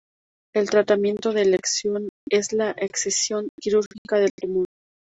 /esθiˈsjon/